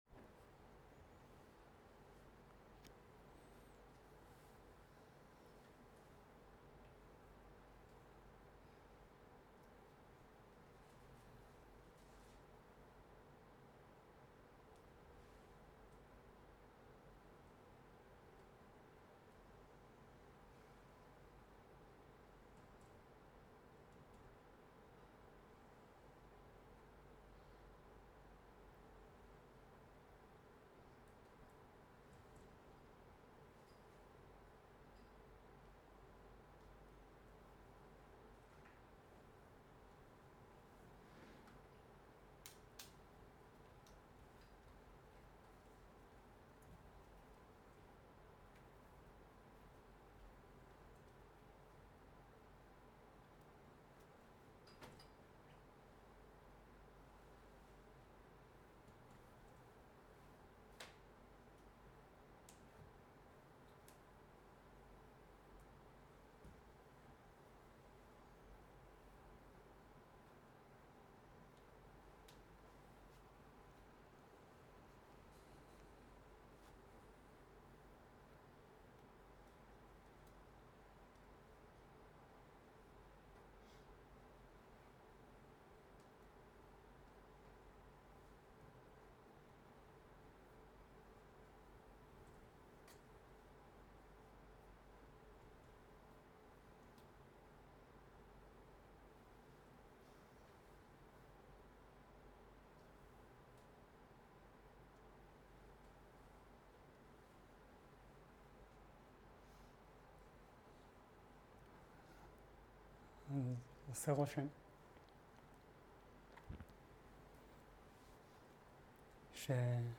שיחות דהרמה שפת ההקלטה